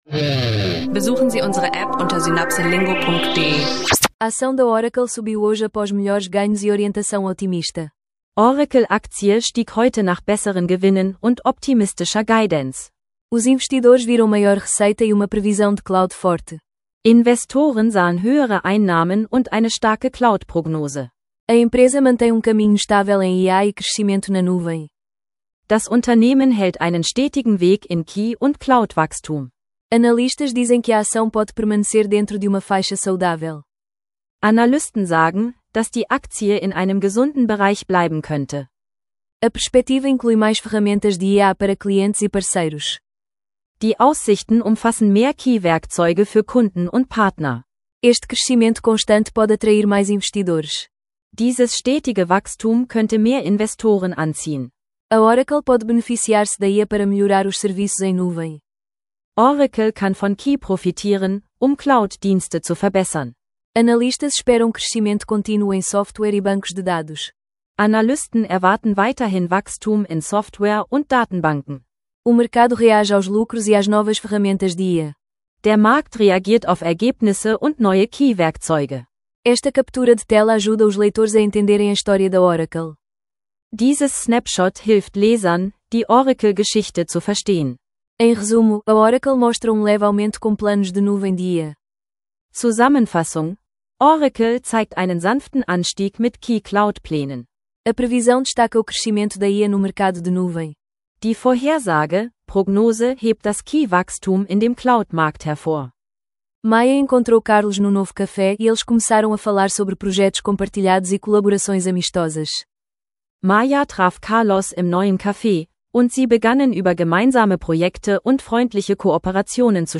Beschreibung vor 7 Monaten In dieser Episode lernen Anfänger und Fortgeschrittene Portugiesisch lernen online durch praxisnahe Dialoge zu Alltagsthemen wie Freundschaften, globale Märkte und Demokratie. Entdecke Tipps zum Portugiesisch Lernen mit Podcast, übe Vokabeln und nimm den Audio-Sprachkurs mit Fokus auf Alltagssprache.